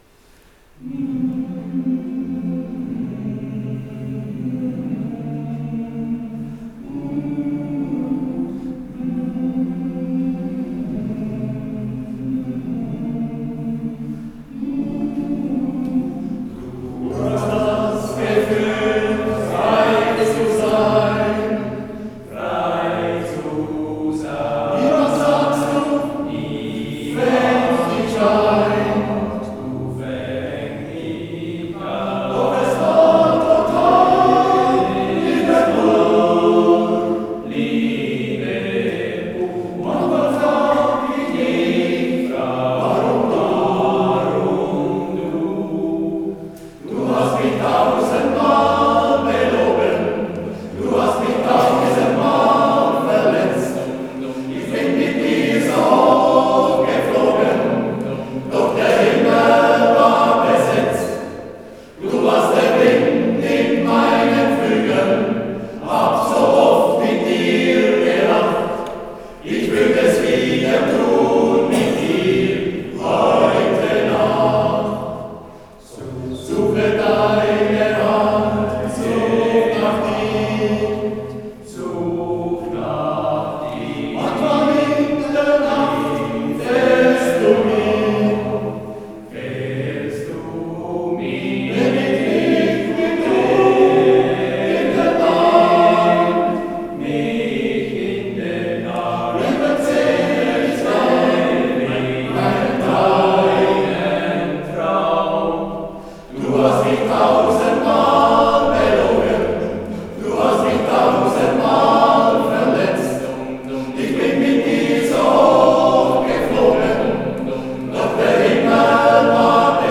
Muttertagskonzert 2025